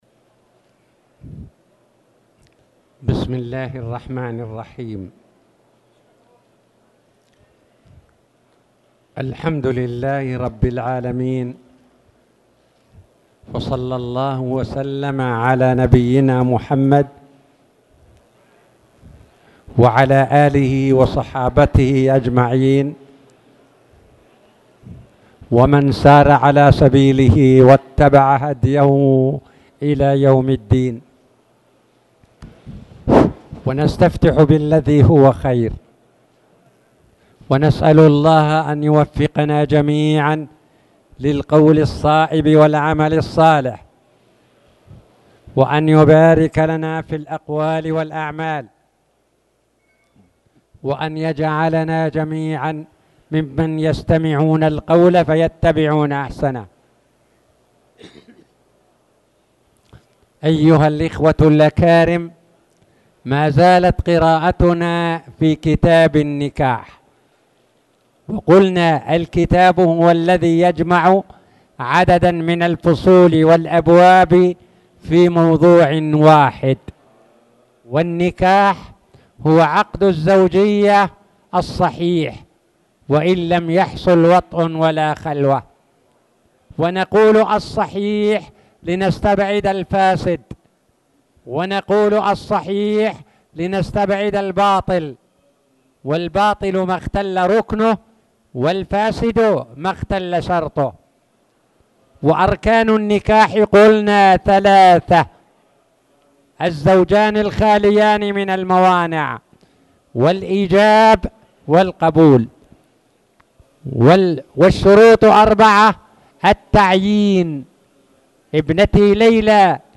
تاريخ النشر ١٥ ربيع الأول ١٤٣٨ هـ المكان: المسجد الحرام الشيخ